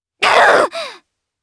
Nia-Vox_Damage_jp_03.wav